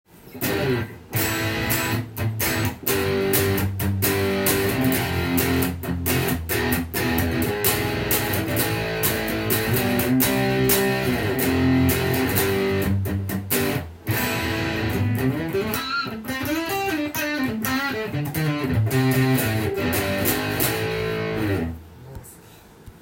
【Aria Pro II/PE-DLX】チェリーサンバースト
ピックアップマイクにセイモアダンカンが搭載された
試しに弾いてみました